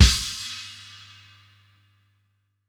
crash kick 1.wav